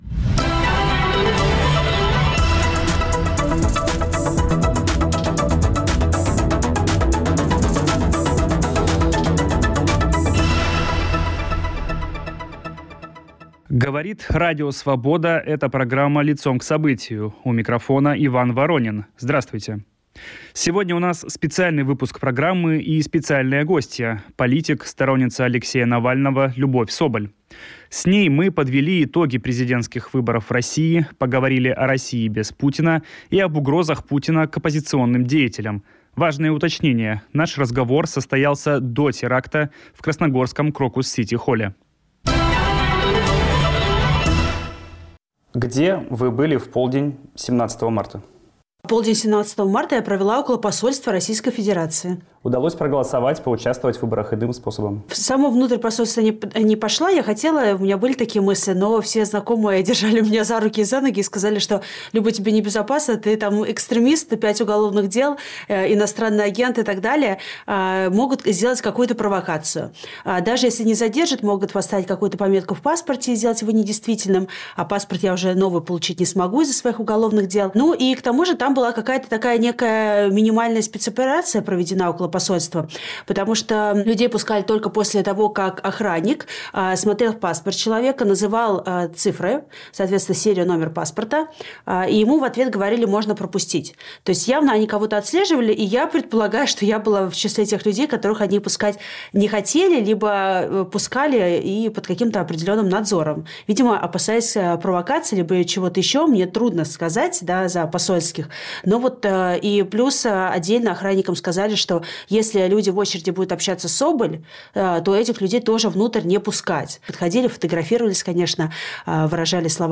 Эксклюзивное интервью с оппозиционным политиком, сторонницей Алексея Навального Любовью Соболь.